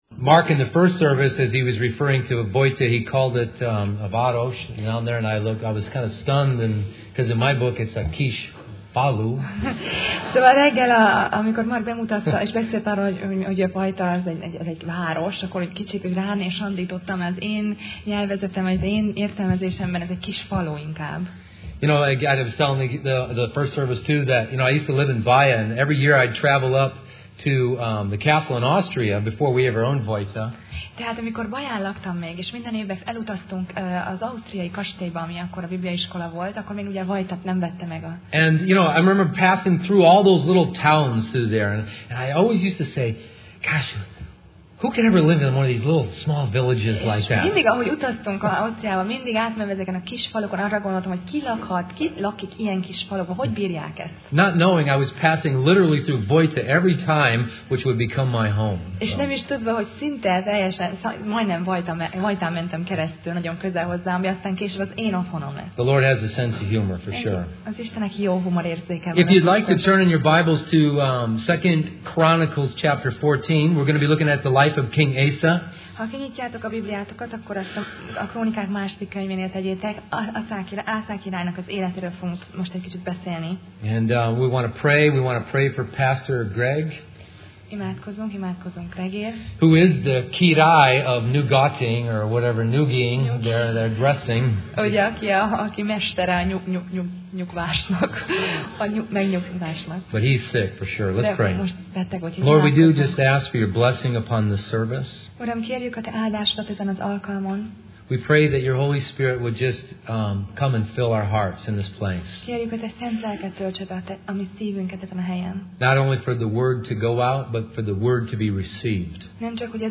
Sorozat: Tematikus tanítás Passage: 2Krónikák (2Chronicles) 14-16 Alkalom: Vasárnap Reggel